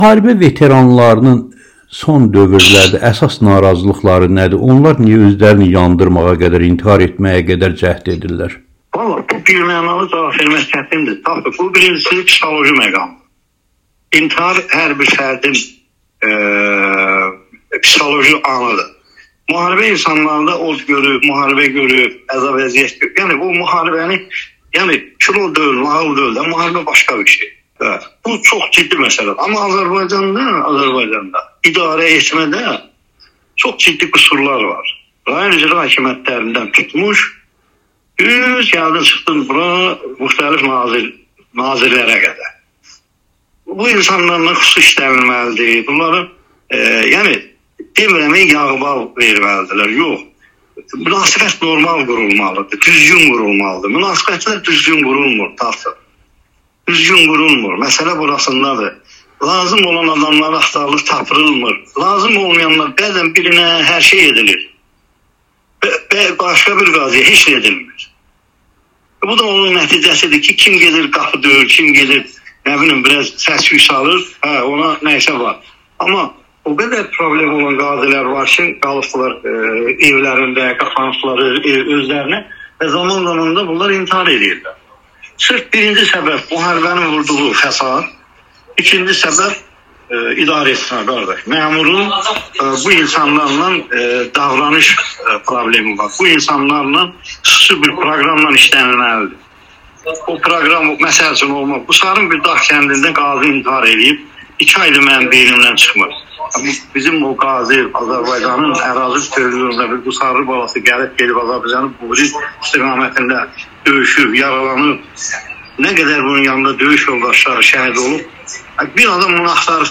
Amerikanın Səsinə müsahibəsində